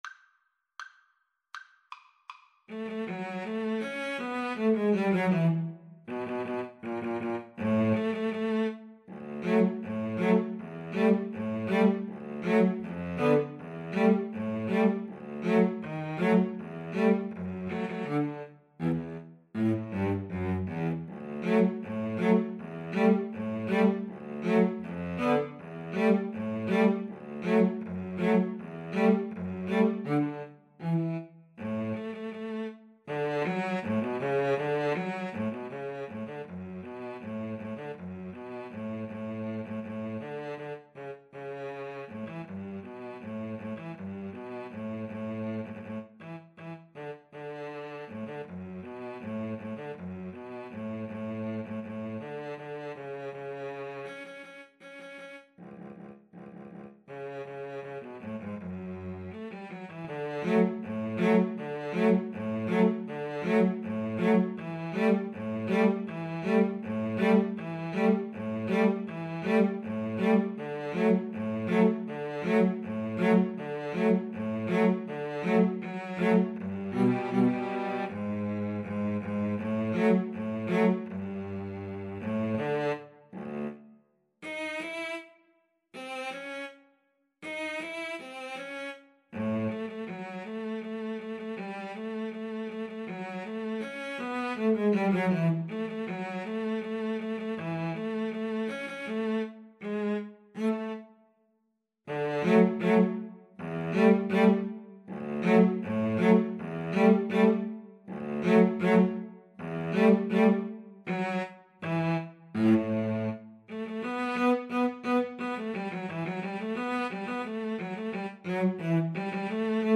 "The Parade of the Tin Soldiers" (Die Parade der Zinnsoldaten), also known as "The Parade of the Wooden Soldiers", is an instrumental musical character piece, in the form of a popular jaunty march, written by German composer Leon Jessel, in 1897.
D major (Sounding Pitch) (View more D major Music for Violin-Cello Duet )
Not Fast =80
Violin-Cello Duet  (View more Intermediate Violin-Cello Duet Music)
Classical (View more Classical Violin-Cello Duet Music)